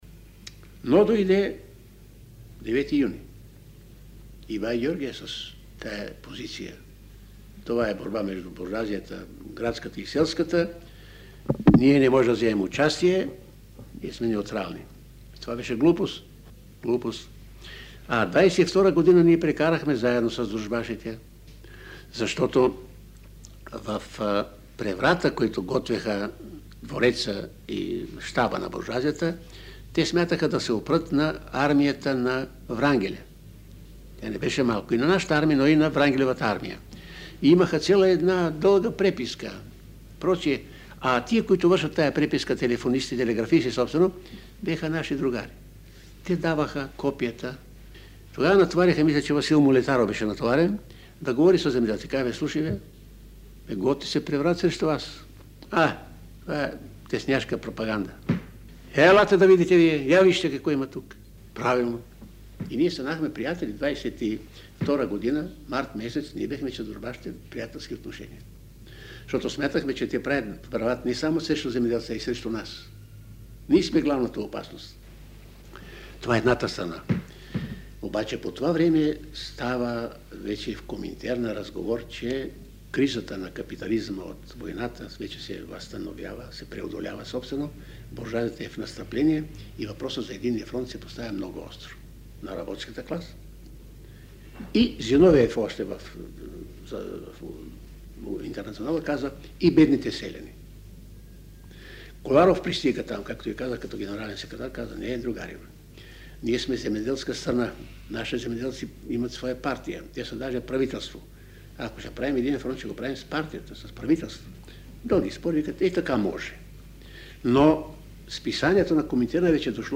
Тази теза застъпва в интервю за БНР от 1982 година философът и политик от БКП акад. Сава Гановски. Той говори за позицията на „бай Георги“ (Георги Димитров), която се оказва решаваща в последвалите събития: